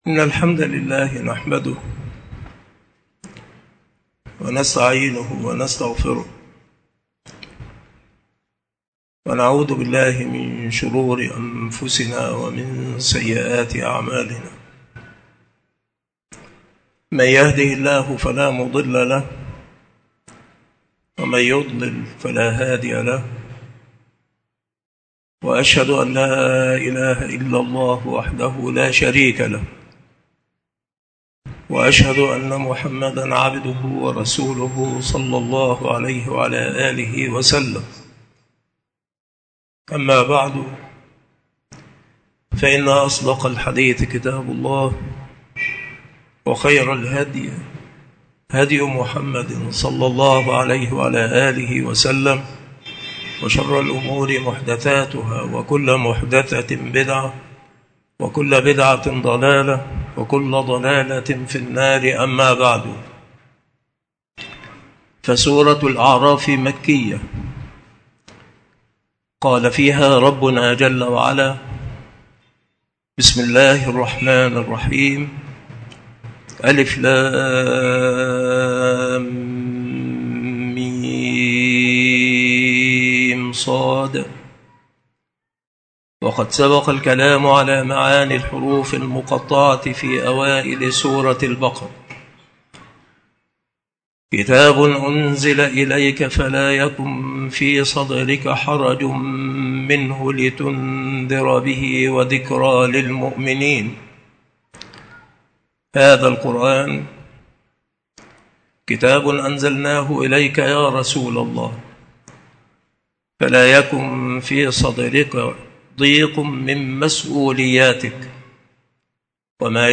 التفسير
مكان إلقاء هذه المحاضرة بالمسجد الشرقي بسبك الأحد - أشمون - محافظة المنوفية - مصر